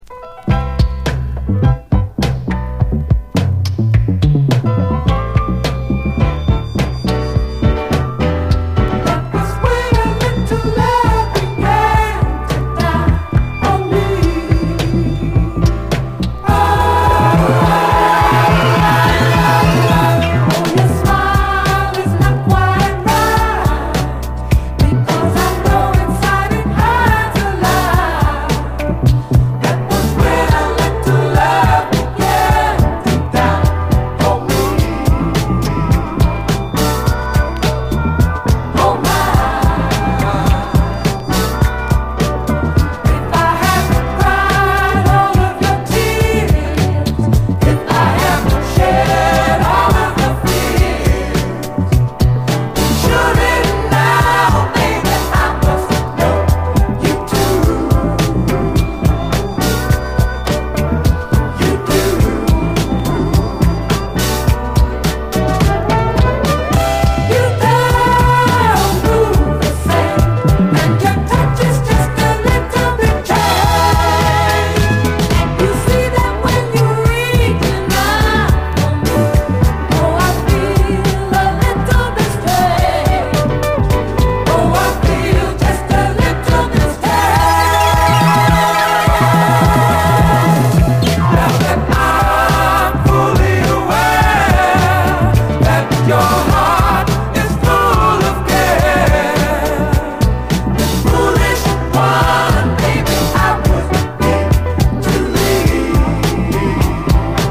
ジャケ上辺下辺テープ補修/ 傑作ニューソウル！
最高ドリーミー・ソウル